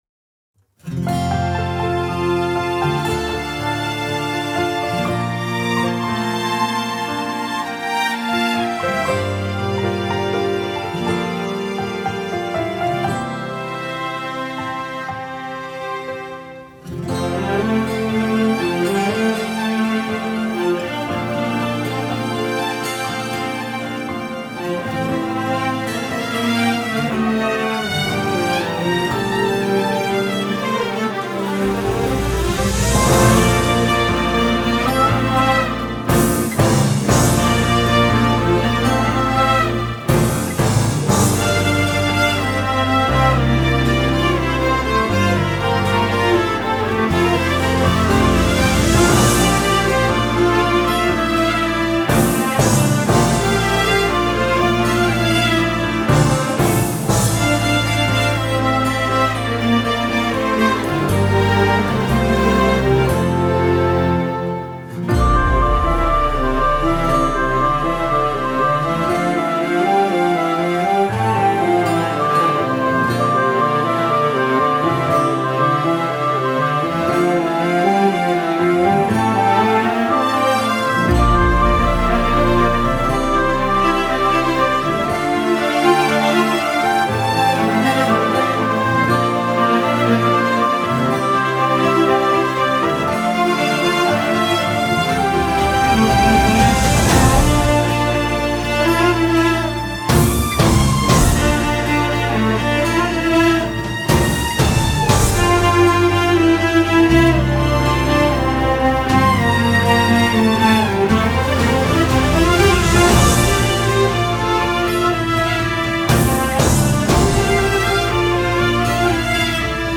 زفات ذكاء اصطناعي